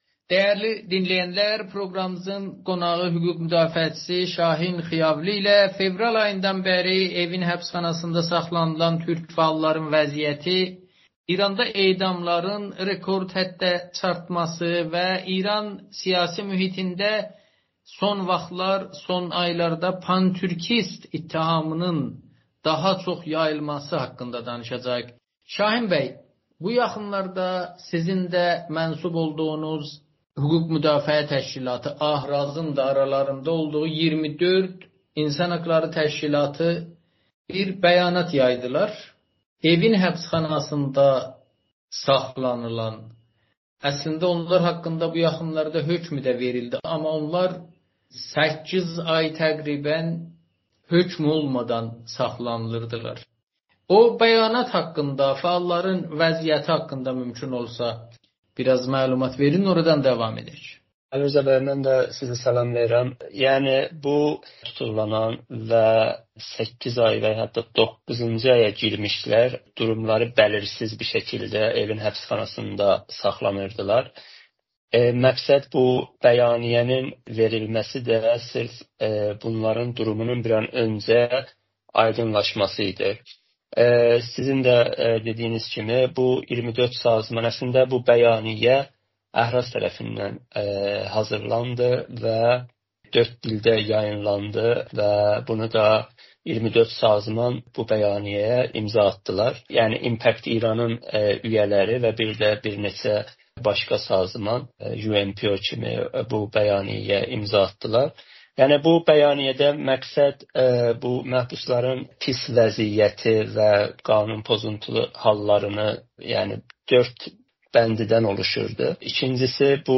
Amerikanın Səsinə danışır